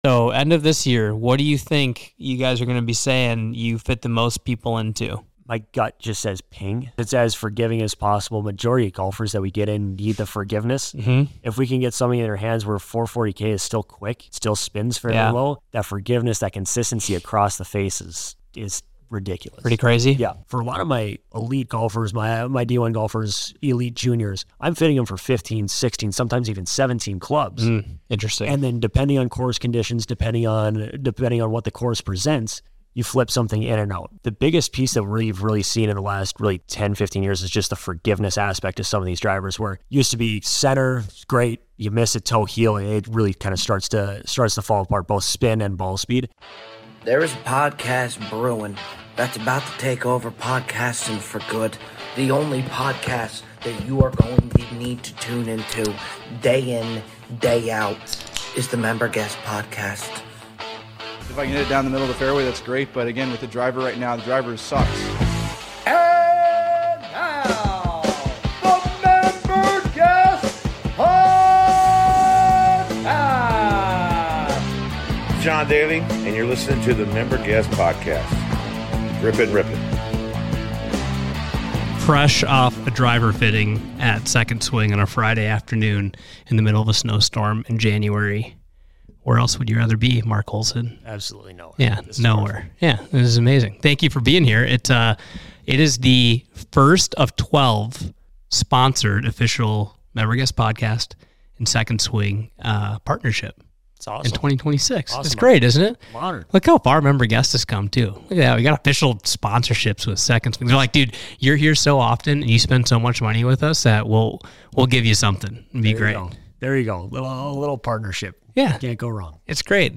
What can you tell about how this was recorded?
in studio to talk high level am golf, matching up equipment to your game and what we're looking forward to equipment wise in 2026.